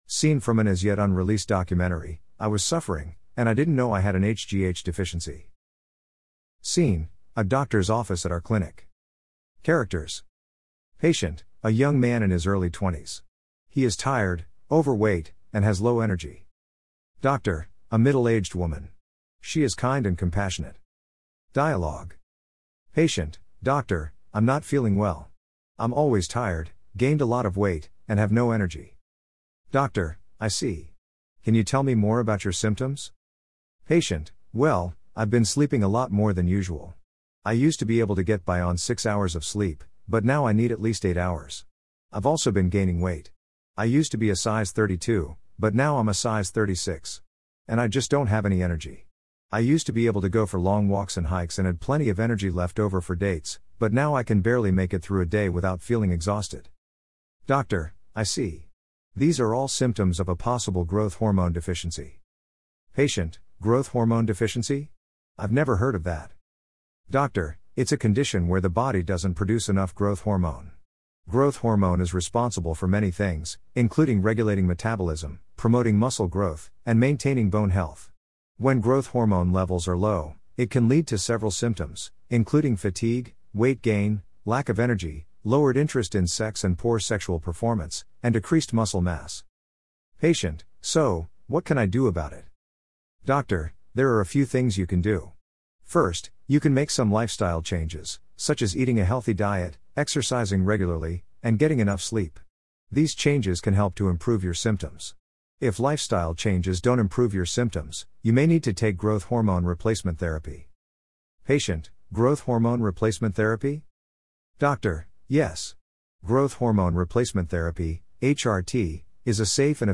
Until I visited the clinic that sponsors this website Scene: A doctor's office at our clinic. Characters: Patient: A young man in his early 20s.